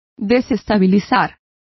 Complete with pronunciation of the translation of unsettle.